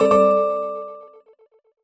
jingle_chime_15_positive.wav